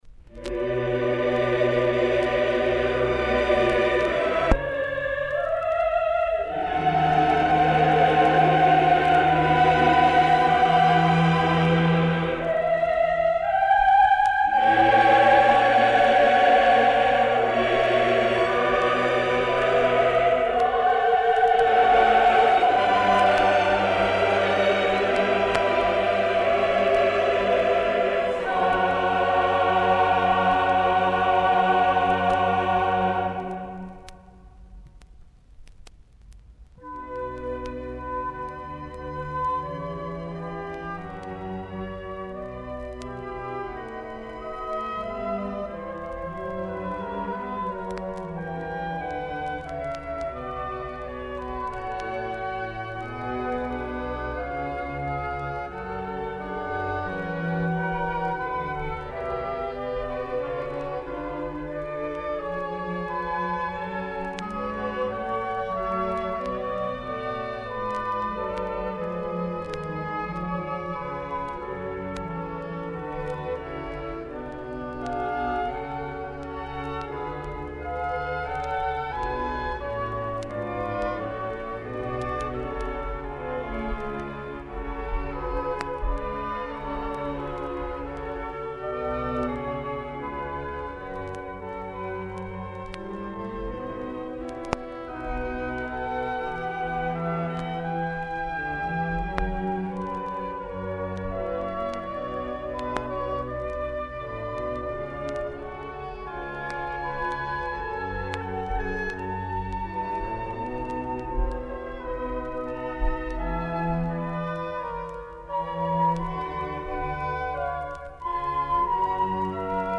То же самое, но в исполнении хора Р.Шоу, первое-Мюнхенский баховский хор.